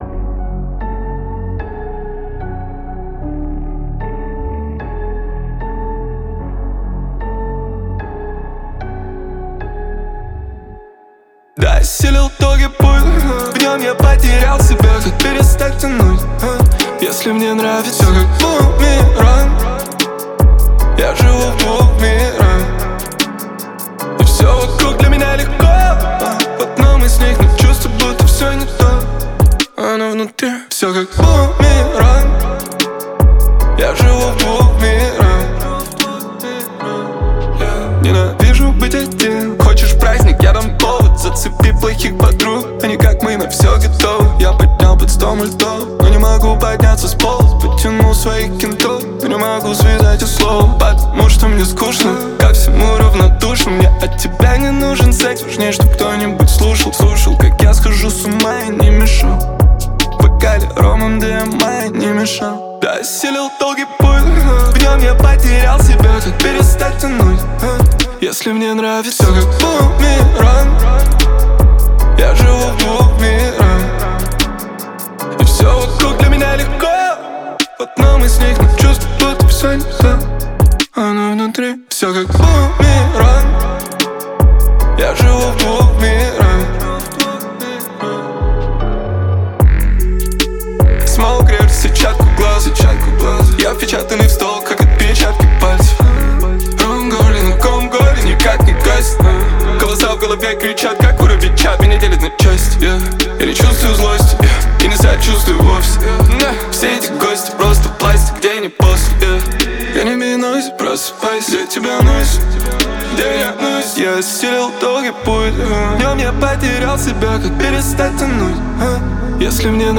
выполненная в жанре хип-хоп.